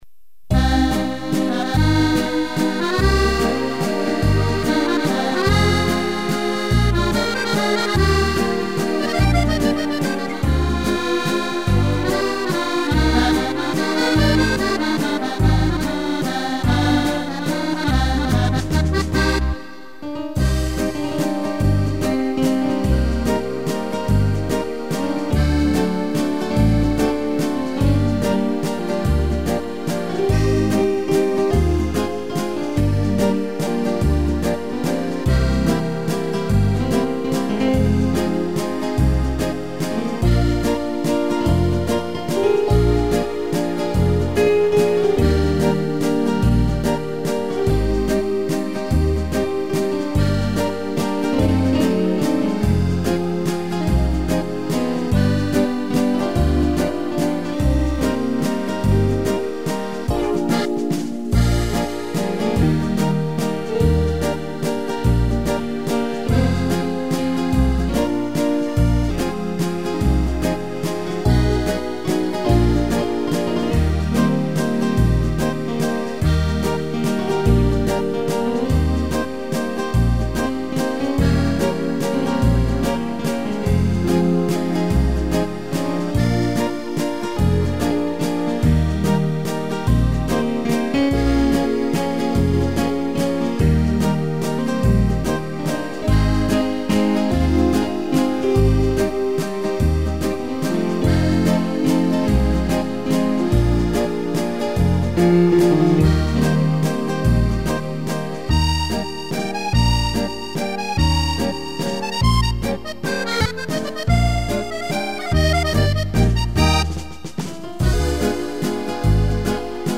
piano, violino e cello
(instrumental)